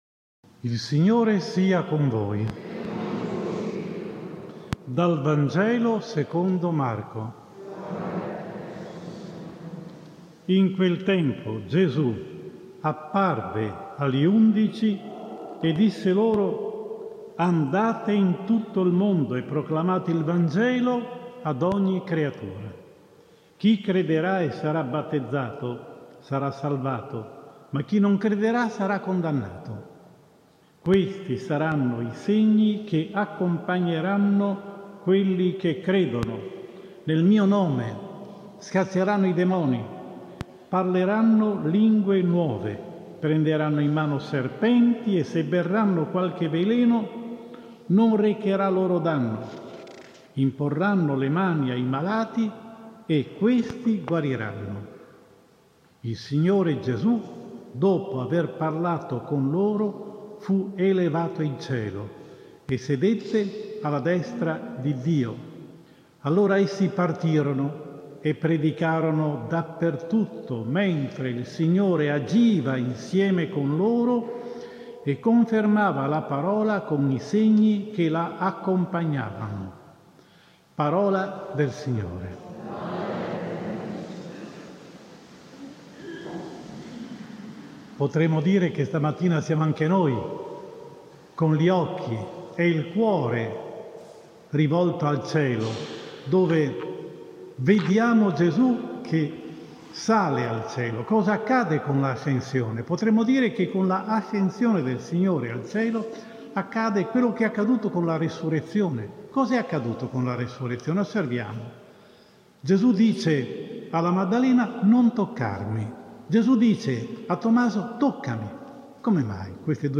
Domenica 16 Maggio 2021 ASCENSIONE DEL SIGNORE (Anno B) – omelia